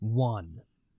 Voices / Male